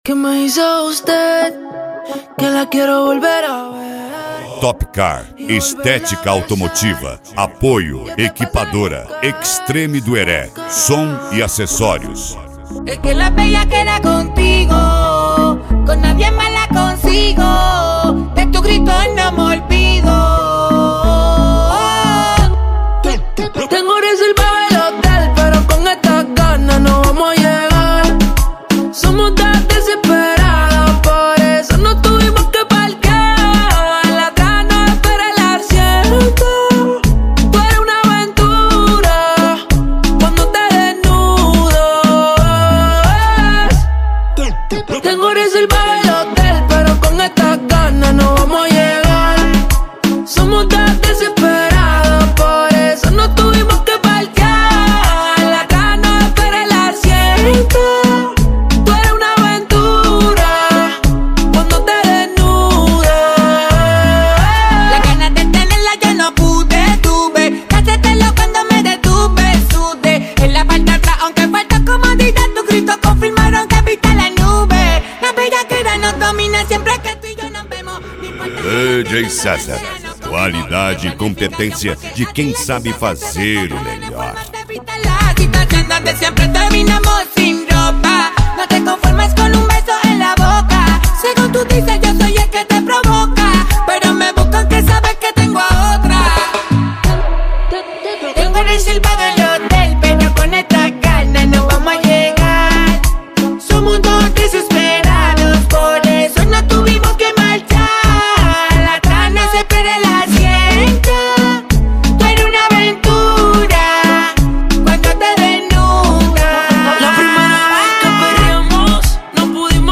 Funk
Mega Funk
Melody
Modao